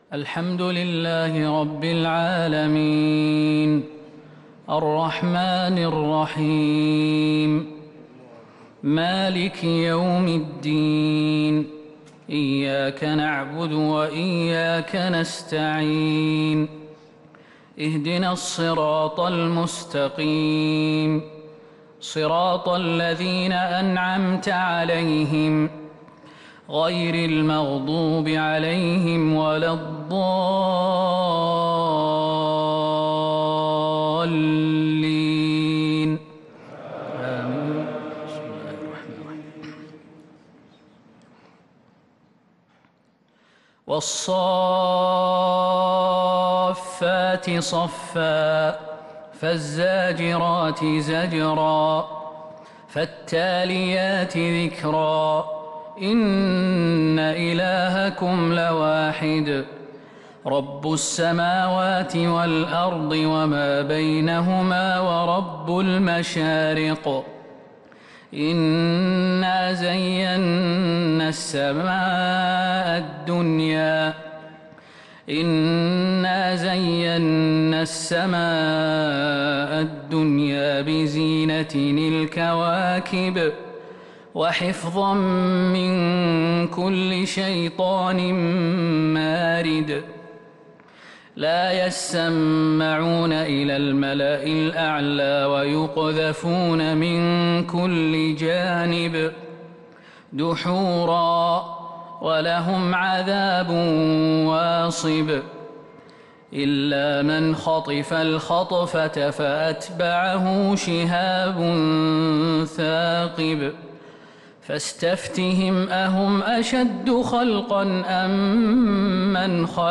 تهجد ليلة 25 رمضان 1443هـ سورة الصافات كاملة + سورة ص ( 1 - 44 ) | Tahajjud 25st night Ramadan 1443H -Surah As-Saaffat & Sad > تراويح الحرم النبوي عام 1443 🕌 > التراويح - تلاوات الحرمين